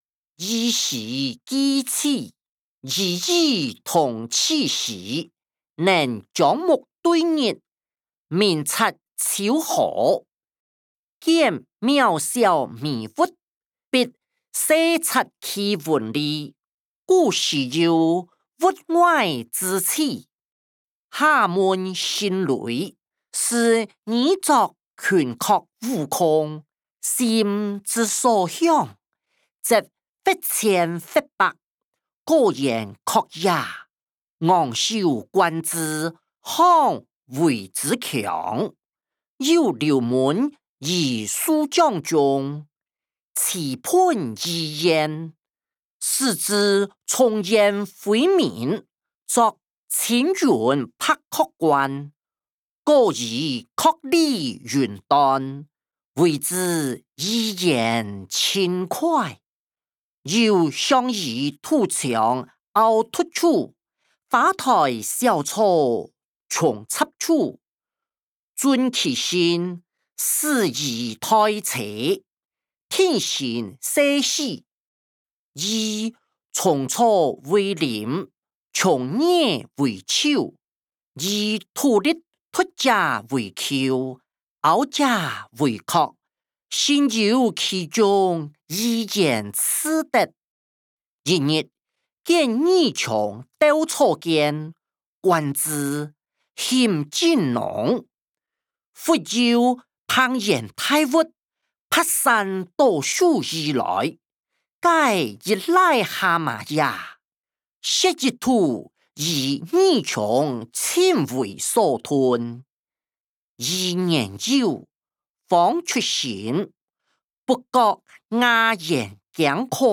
歷代散文-兒時記趣音檔(大埔腔)